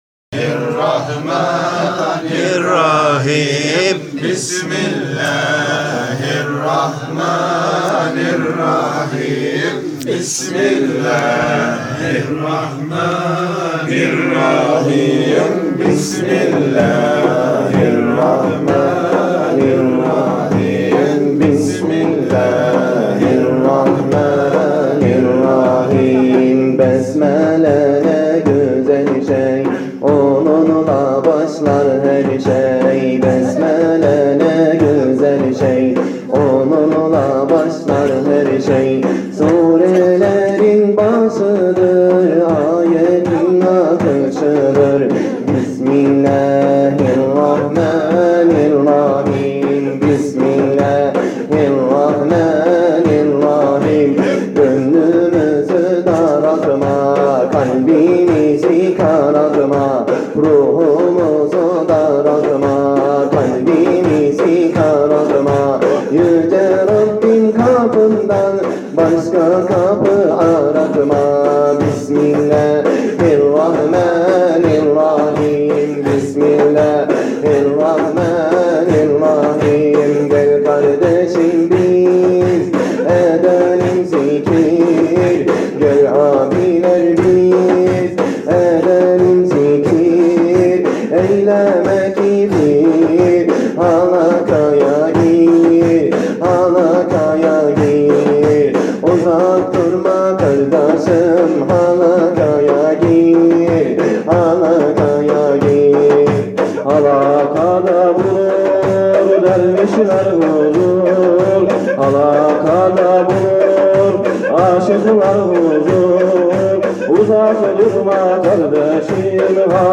Niğde Zikirleri